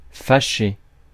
Ääntäminen
IPA: /fɑ.ʃe/